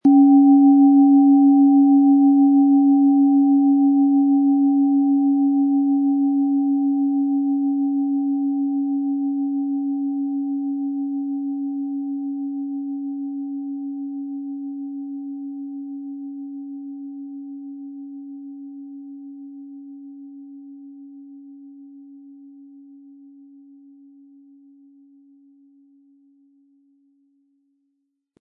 OM Ton
• Mittlerer Ton: Tageston
Wie klingt die Schale?
Sie möchten den schönen Klang dieser Schale hören? Spielen Sie bitte den Originalklang im Sound-Player - Jetzt reinhören ab.
SchalenformBihar
MaterialBronze